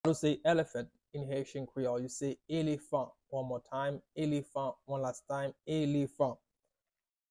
“Elephant” in Haitian Creole – “Elefan” pronunciation by a native Haitian Creole teacher
“Elefan” Pronunciation in Haitian Creole by a native Haitian can be heard in the audio here or in the video below:
How-to-say-Elephant-in-Haitian-Creole-–-Elefan-pronunciation-by-a-native-Haitian-Creole-teacher.mp3